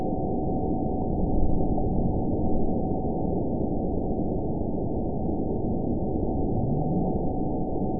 event 917858 date 04/19/23 time 03:36:54 GMT (2 years ago) score 9.05 location TSS-AB03 detected by nrw target species NRW annotations +NRW Spectrogram: Frequency (kHz) vs. Time (s) audio not available .wav